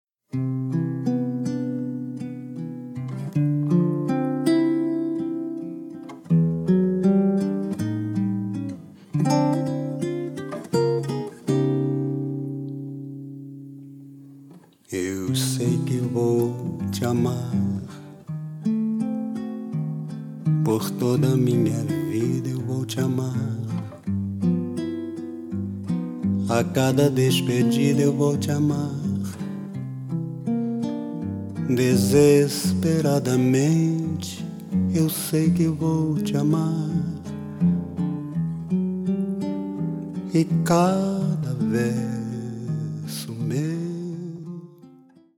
ギターと声だけ。
まろやかなその歌は円熟の極み。ギター弾き語りでジャズやブラジル音楽のスタンダードを歌う。
vo,g